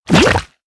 CHQ_FACT_lava_fall_in.ogg